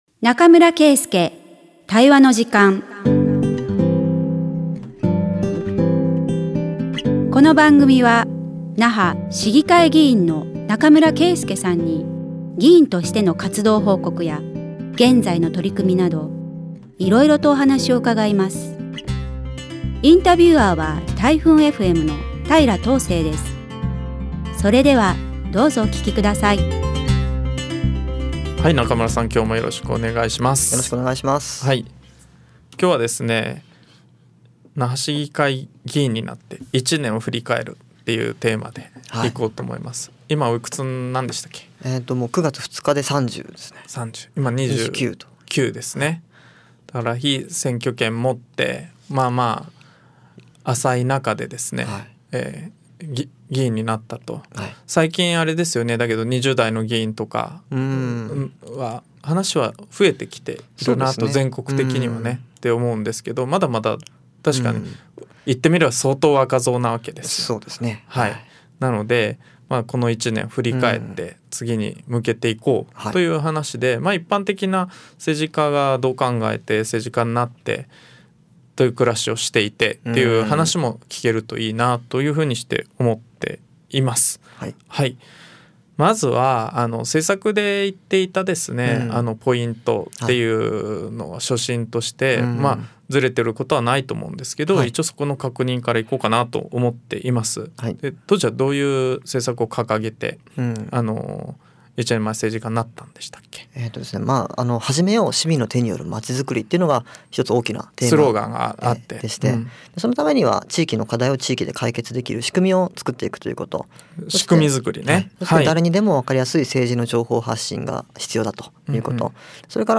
140815中村圭介対話の時間vol.13 那覇市議会議員中村圭介が議員活動や現在の取組みを語る20分